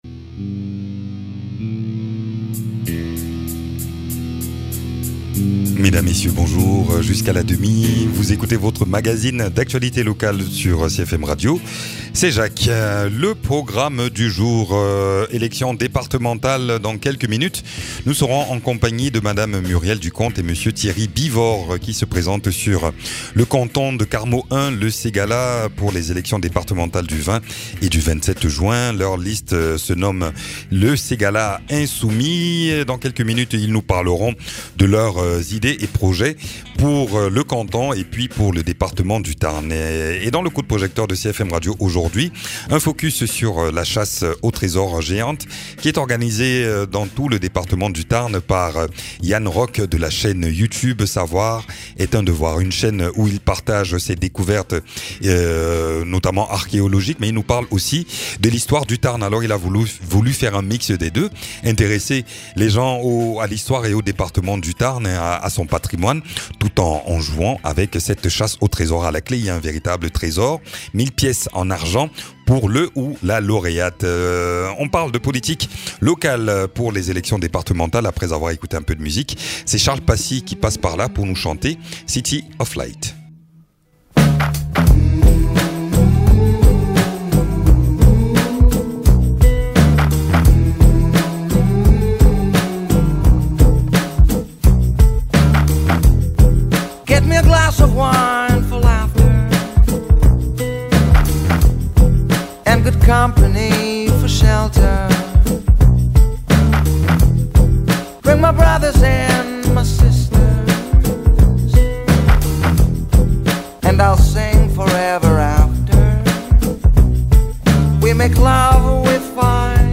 La liste "le Ségala insoumis" invitée dans ce magazine pour parler de leur candidature aux élections départementales sur le canton Carmaux-1 Le Ségala. Et puis, il est question de la chasse au trésor inédite dans le Tarn organisé par la chaîne youtube "savoir est un devoir".